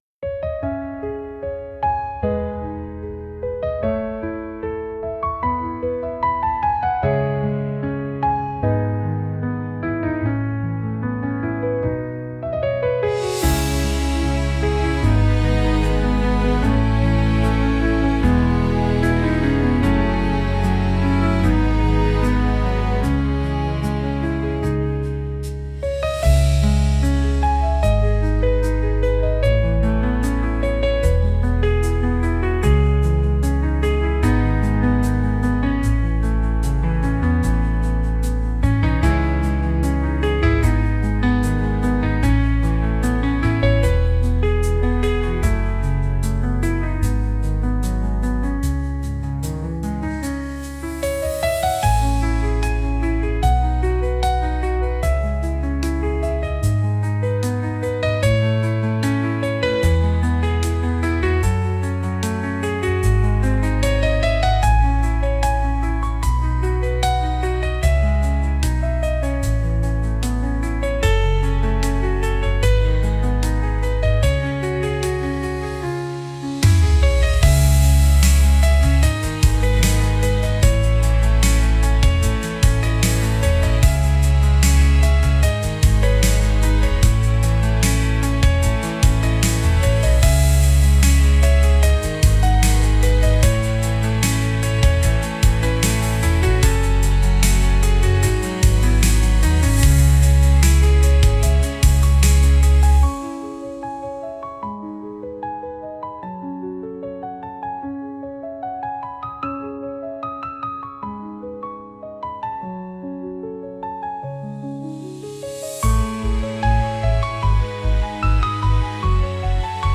Genre: Emotional Mood: Heartfelt Editor's Choice